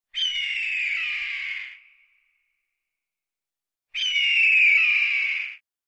eagle fx.mp3